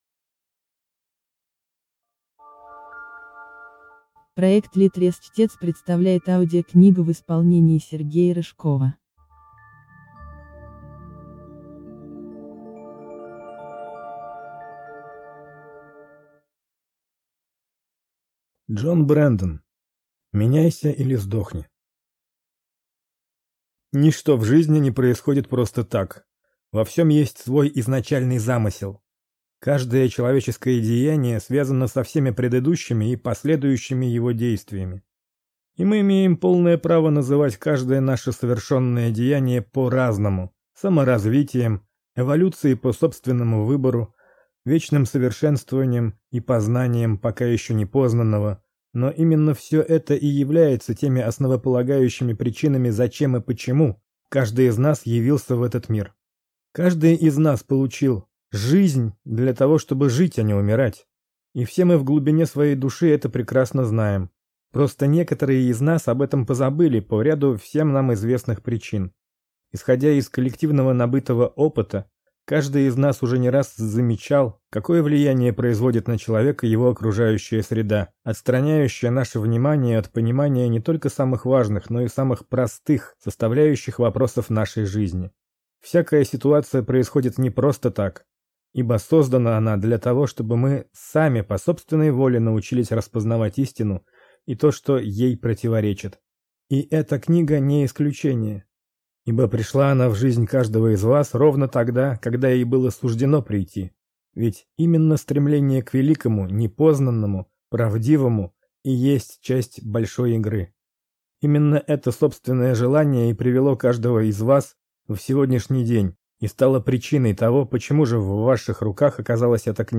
Аудиокнига Меняйся или сдохни | Библиотека аудиокниг